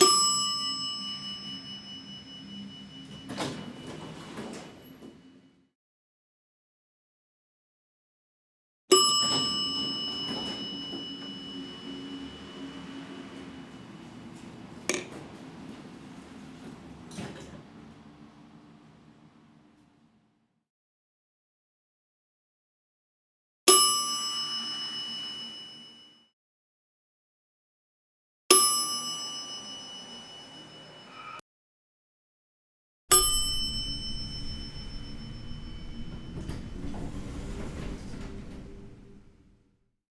Elevator Bell
Bell bing bong ding Elevator ping ring sound effect free sound royalty free Sound Effects